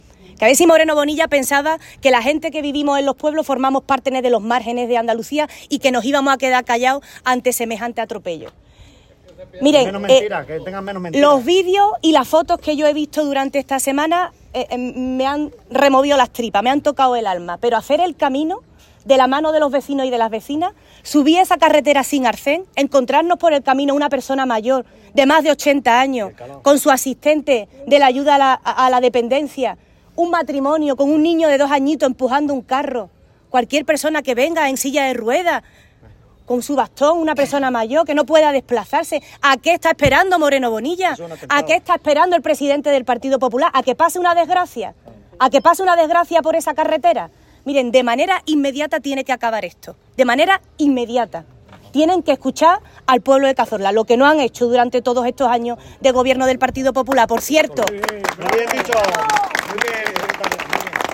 Caminata de denuncia hasta el Hospital Comarcal
Cortes de sonido